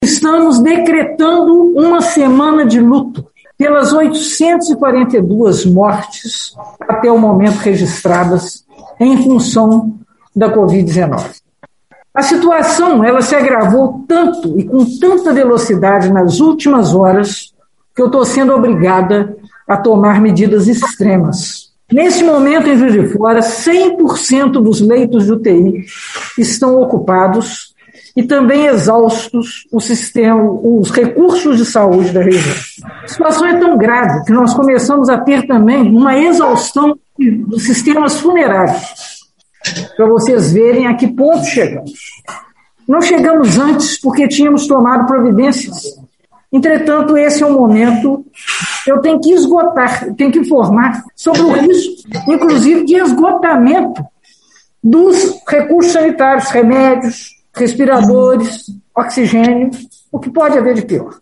O anúncio foi feito pela prefeita Margarida Salomão (PT) em coletiva de imprensa na manhã deste domingo, 7.
prefeita Margarida Salomão